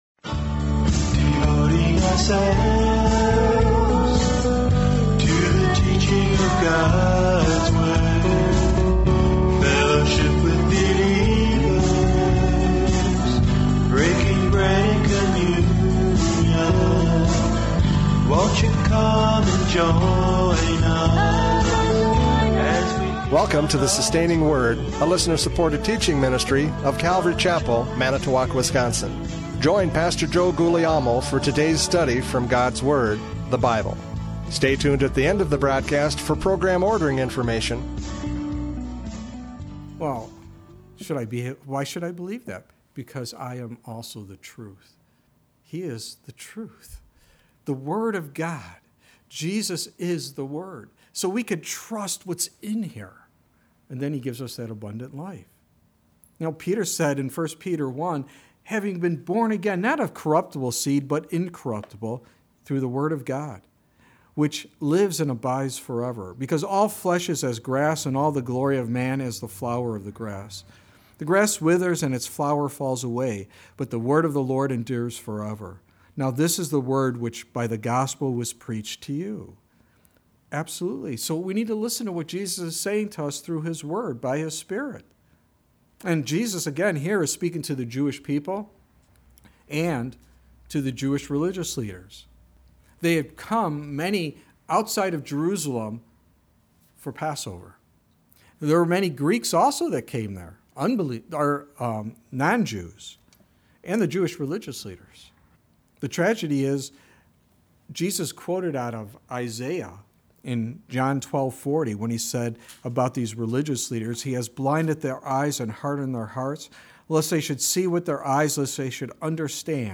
John 12:44-50 Service Type: Radio Programs « John 12:44-50 The Last Call!